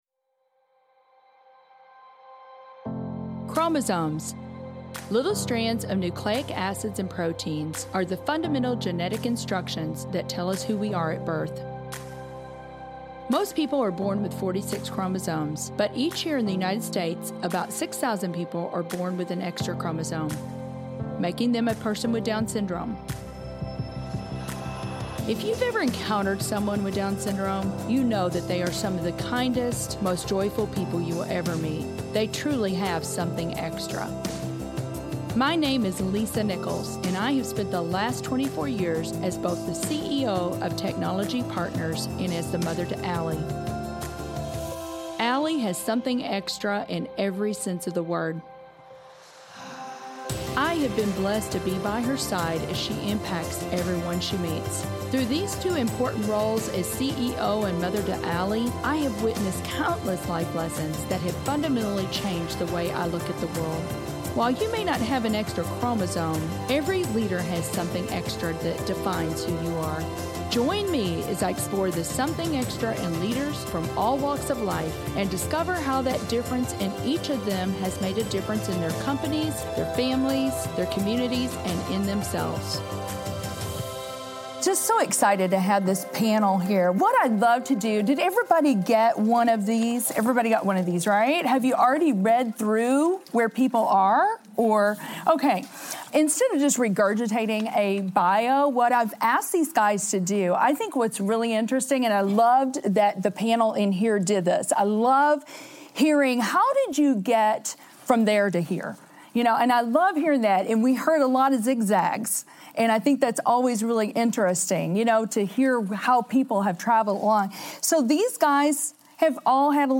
Something Extra w/ HBA Live Panel